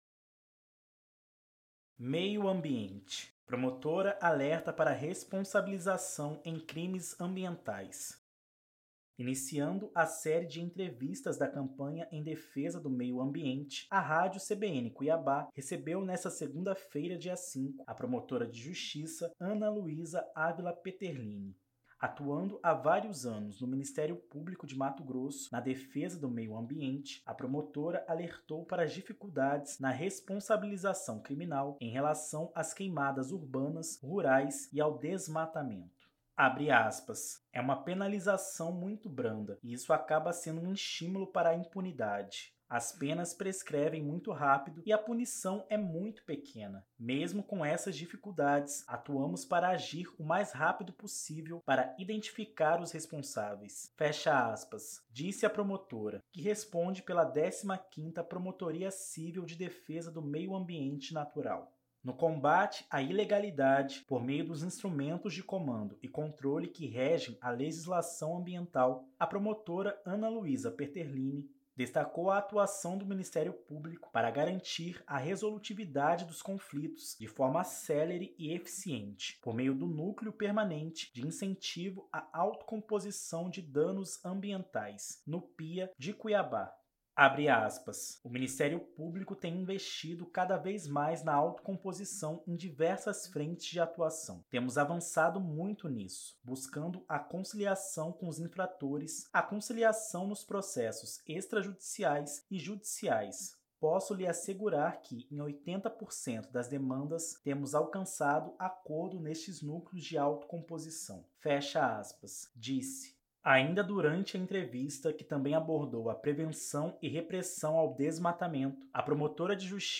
Ainda durante a entrevista, que também abordou a prevenção e repressão ao desmatamento, a promotora de Justiça fez uma análise dos dados que apontam o avanço do desmatamento na Amazônia Legal, da qual o Estado de Mato Grosso é integrante.
A entrevista, realizada em parceria com o Ministério Público, ainda abordou o Cadastro Ambiental Rural - CAR dos imóveis e o Programa de Regularização Ambiental, que foi criado para garantir a regularização dos imóveis rurais, a recuperação das áreas degradadas e também controlar o desmatamento.